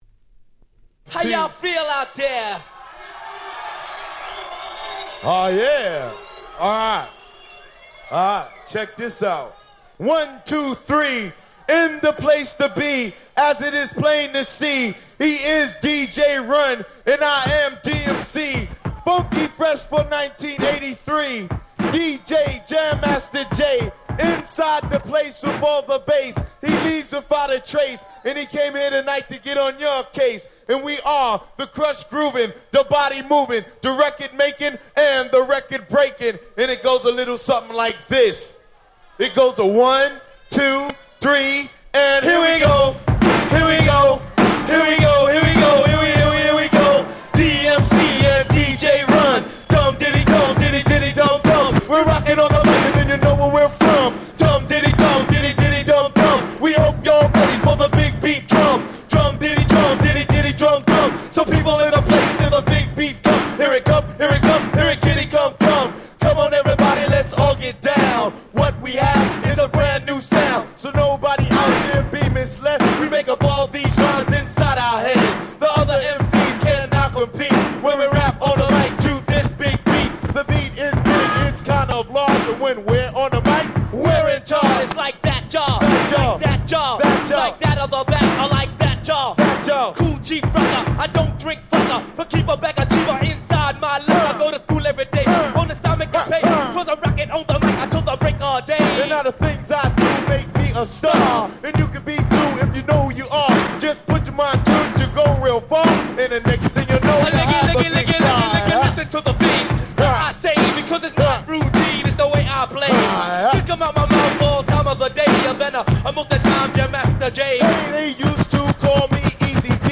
85' Hip Hop Super Classics !!
問答無用のHip Hop古典！！
Old School オールドスクール 80's Boom Bap ブーンバップ